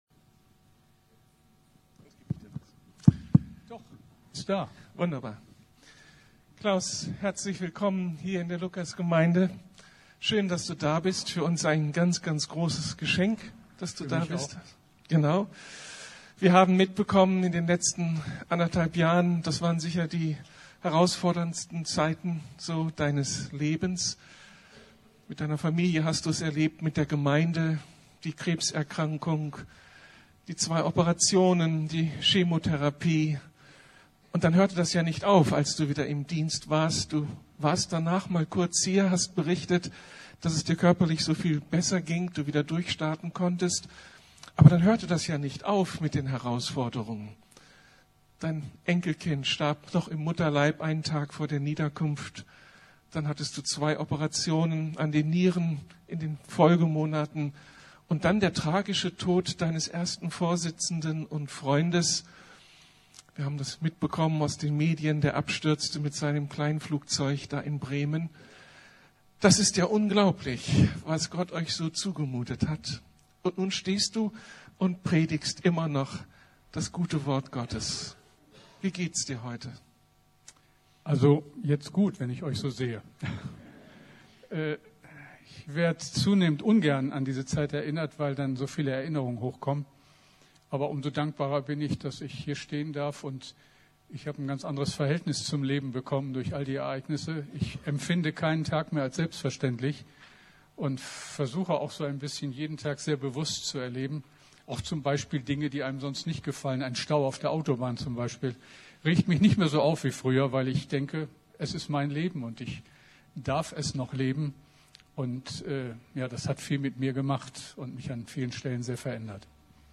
Aufmerken im Advent ~ Predigten der LUKAS GEMEINDE Podcast